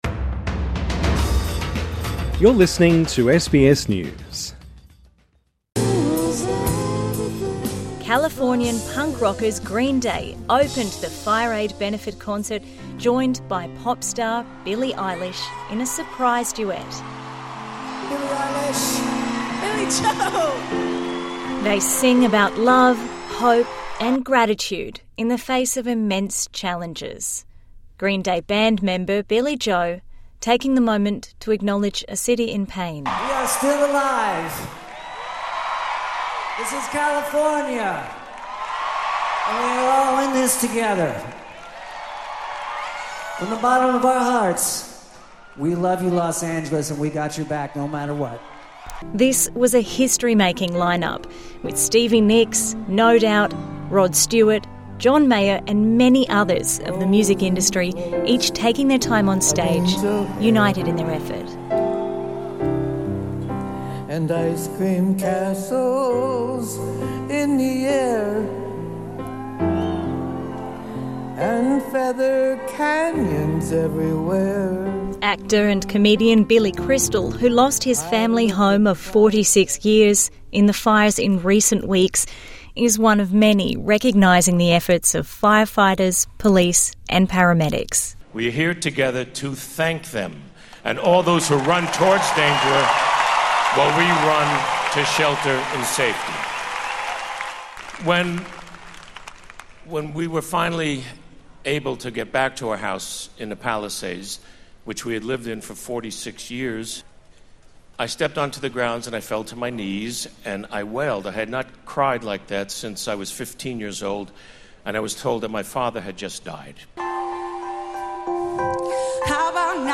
TRANSCRIPT Californian punk rockers Green Day opened the FireAid benefit concert, joined by pop star Billie Eilish in a surprise duet.